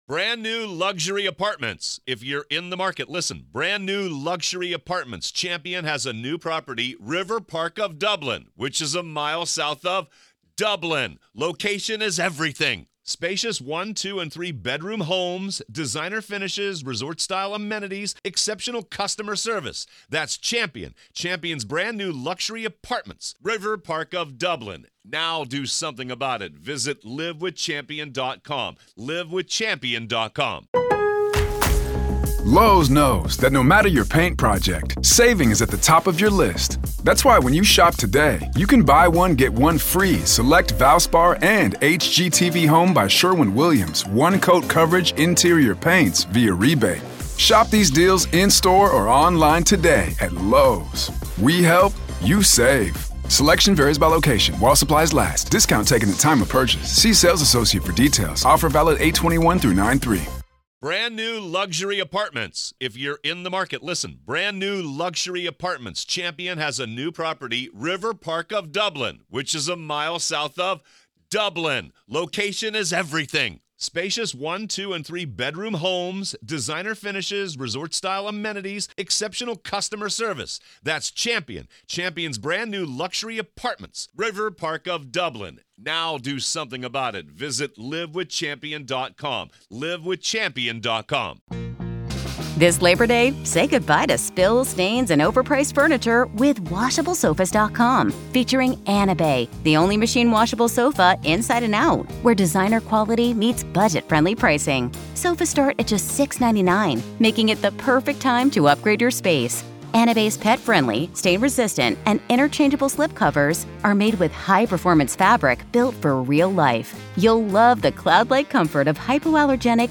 In this exclusive interview